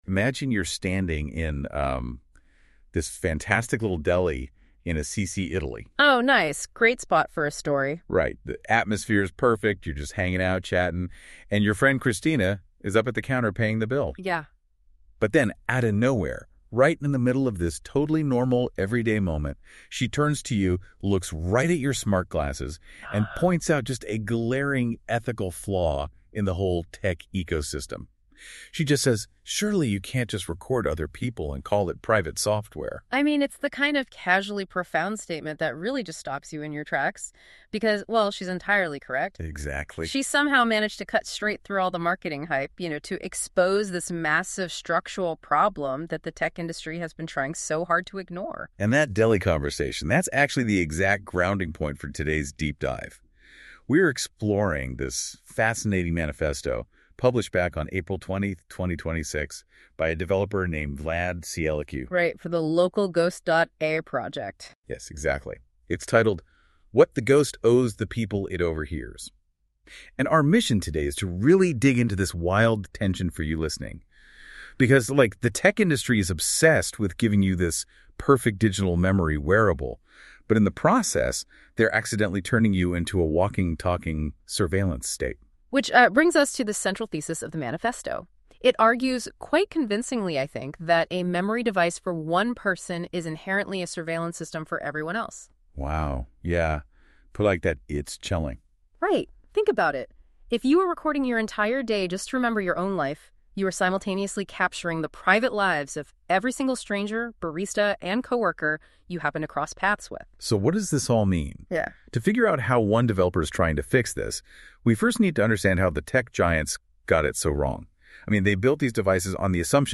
OFFLINE-READY NOTEBOOKLM AUDIO